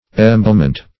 Meaning of emblement. emblement synonyms, pronunciation, spelling and more from Free Dictionary.
emblement.mp3